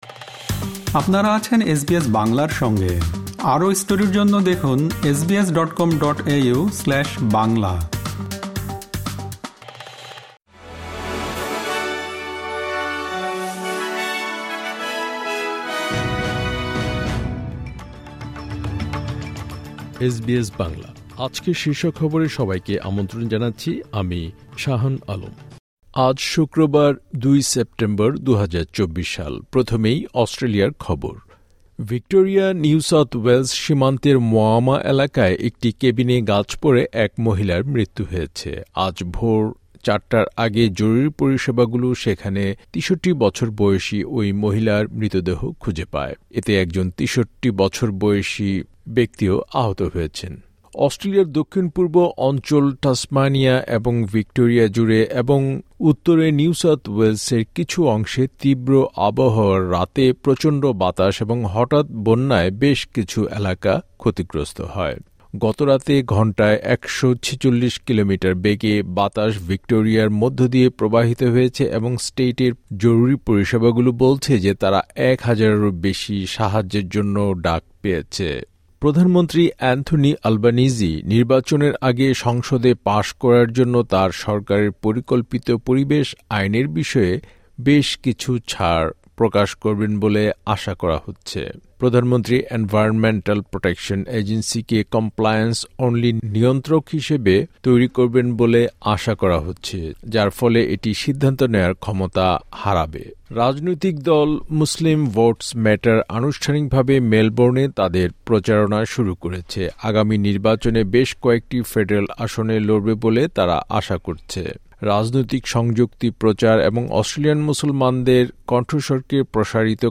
অস্ট্রেলিয়ার জাতীয় ও আন্তর্জাতিক সংবাদের জন্য আজকের এসবিএস বাংলা শীর্ষ খবর শুনতে উপরের অডিও-প্লেয়ারটিতে ক্লিক করুন।